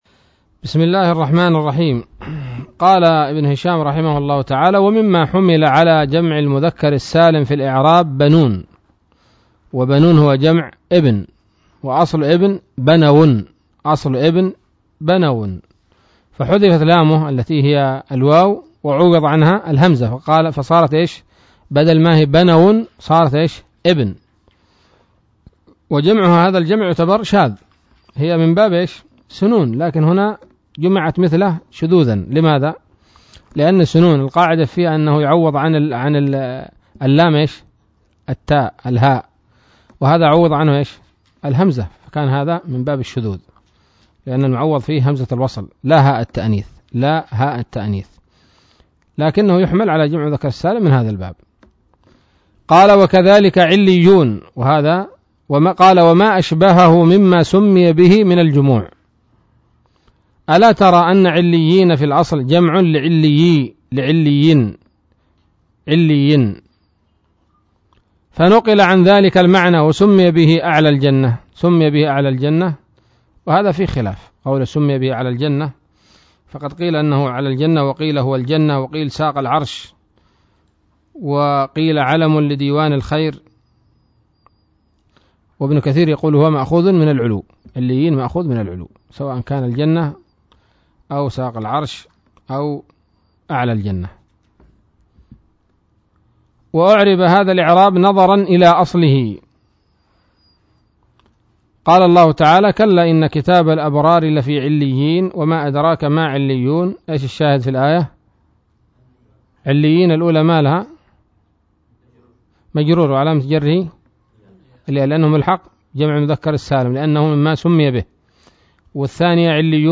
الدرس الثاني والعشرون من شرح قطر الندى وبل الصدى